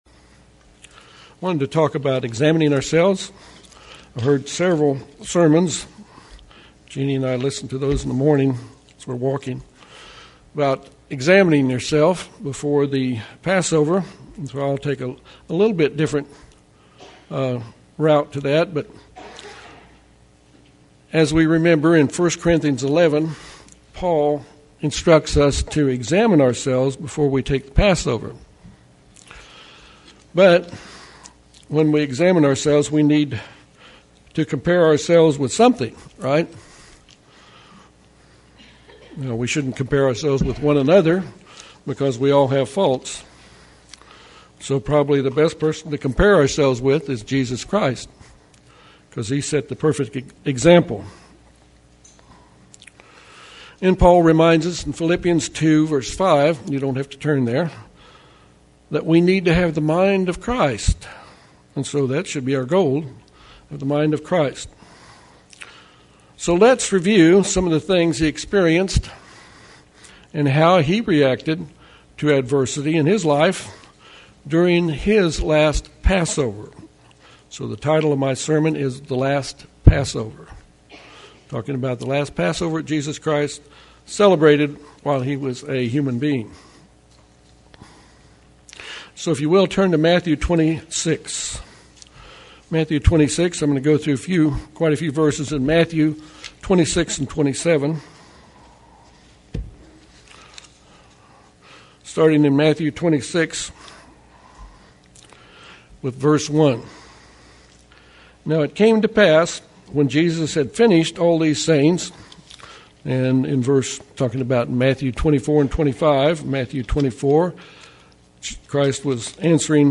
This sermon examines the last Passover of Jesus Christ